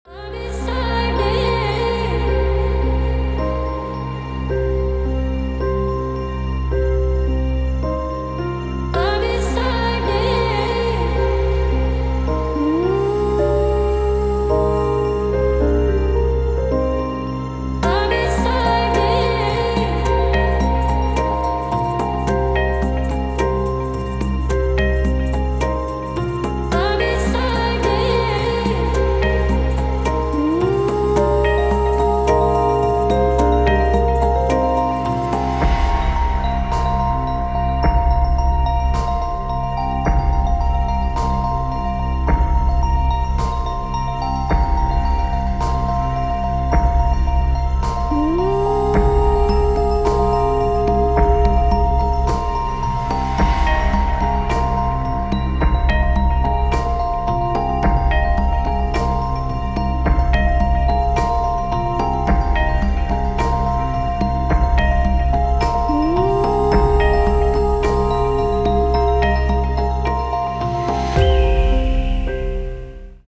Расслабляющий эмбиент - рингтон